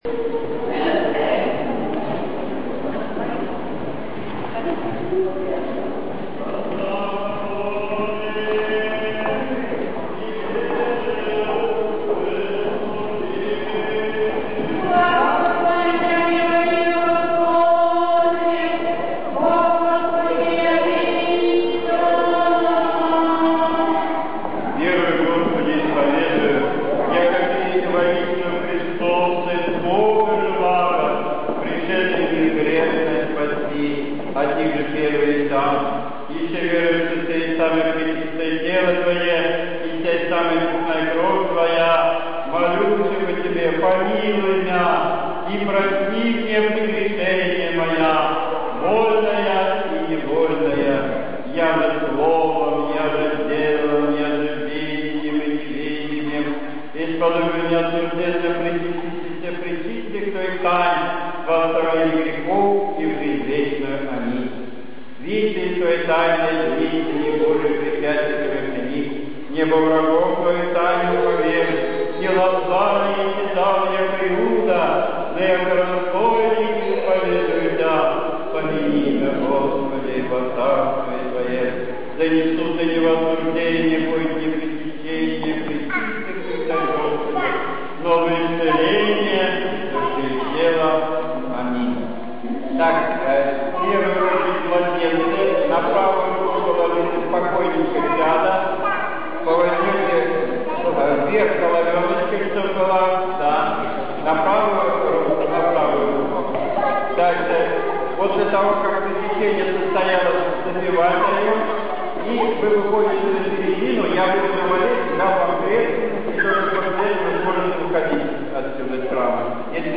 Божественная Литургия 9 августа 2009 года
Таинство причащения